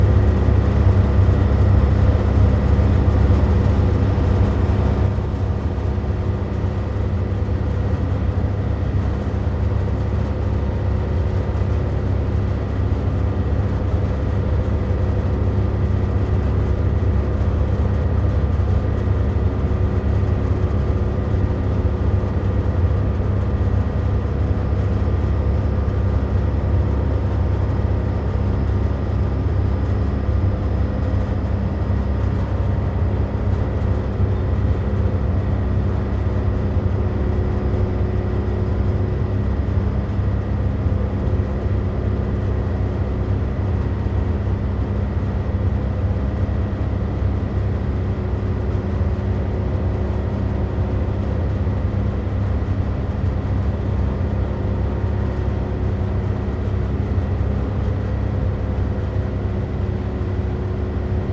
风扇 通风 立体声 5
描述：利勒哈默尔挪威的通风氛围
Tag: 背景音 白-noise 音景 氛围 环境 背景 ATMO 氛围 一般噪音 大气 ATMOS